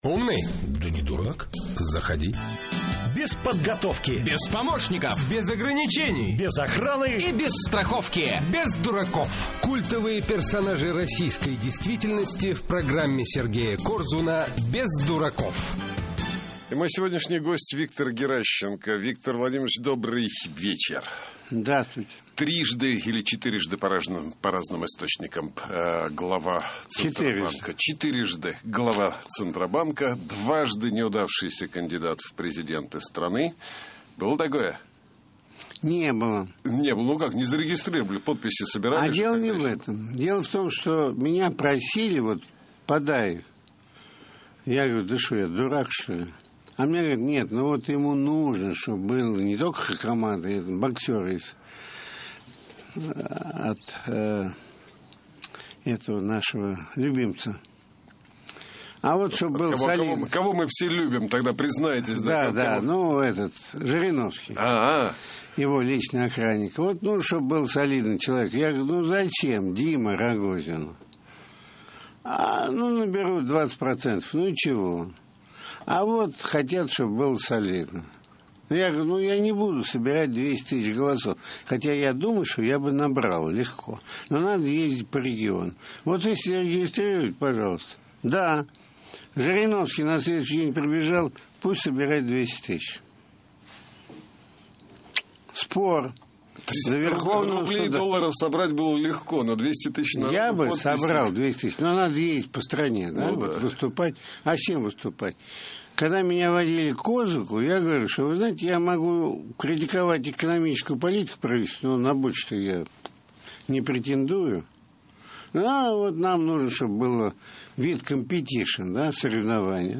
Интервью из архива «Эха Москвы» от 27.09.11
Интервью из архива «Эха Москвы»: «Без дураков» с Виктором Геращенко